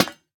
Minecraft Version Minecraft Version snapshot Latest Release | Latest Snapshot snapshot / assets / minecraft / sounds / block / lantern / break5.ogg Compare With Compare With Latest Release | Latest Snapshot
break5.ogg